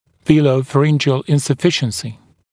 [ˌviːləuˌfæ’rɪnʤ(ɪ)əl ˌɪnsə’fɪʃənsɪ] [-rən’ʤiːəl][ˌви:лоуˌфэ’риндж(и)эл ˌинсэ’фишэнси] [-рэн’джи:эл]нёбно-глоточная недостаточность